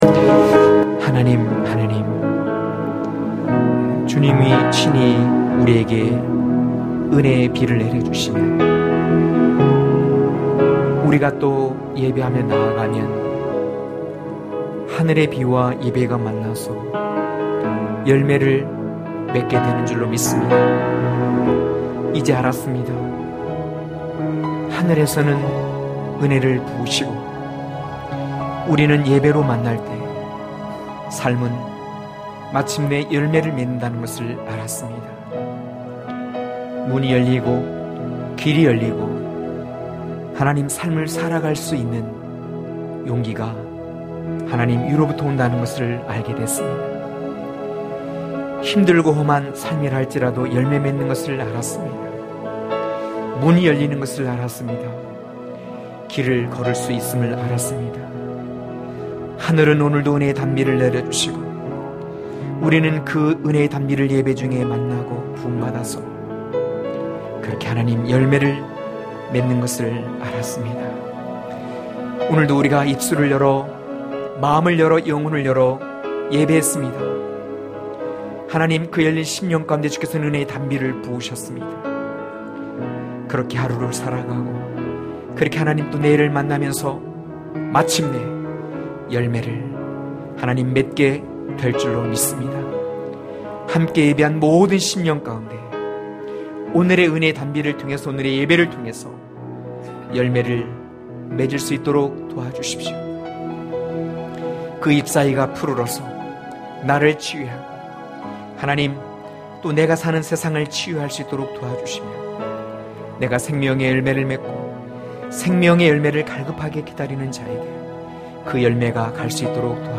강해설교 - 14.잠근 동산을 여실 때(아4장12-16절)